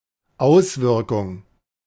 Pronunciation recording of German noun "Auswirkung". Male voice, recorded by native German speaker from Berlin, Germany.
Männliche Stimme, aufgenommen von deutschem Muttersprachler aus Berlin, Deutschland.